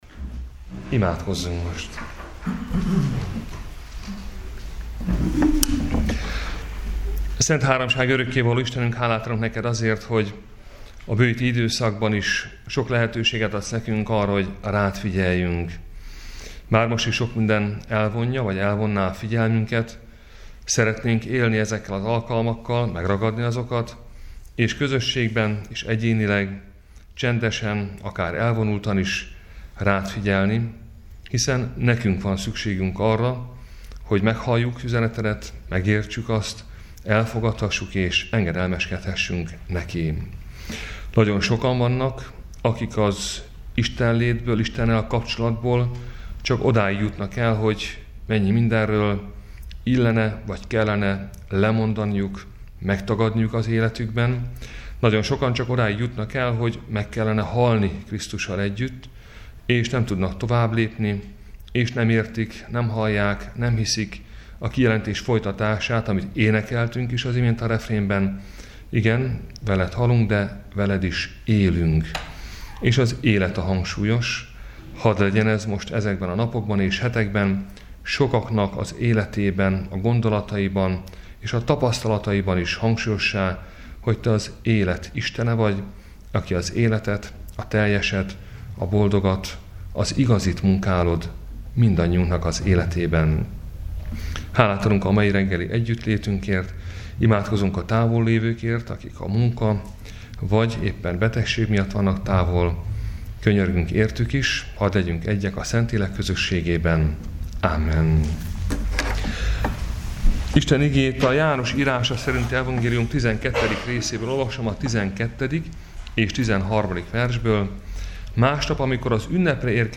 Áhítat, 2019. március 13.